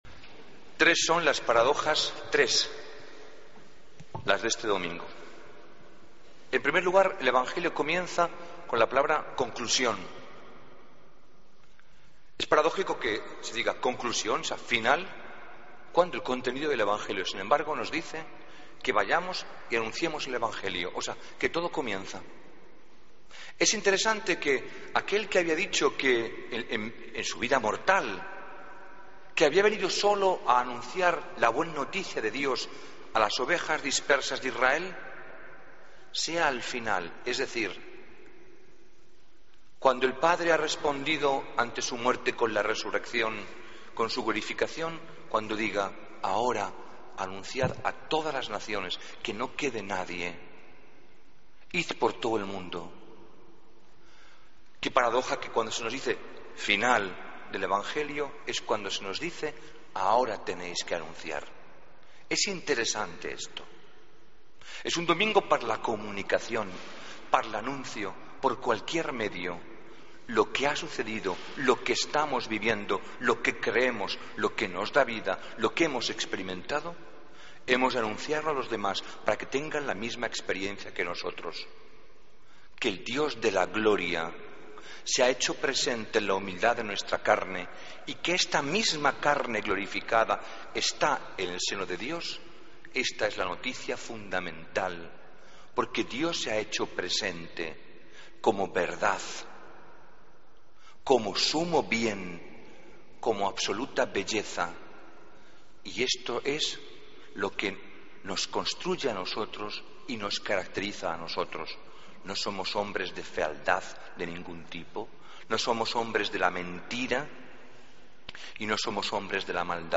Homilía domingo 1 de junio